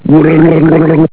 Gurgle sound
gurgle.au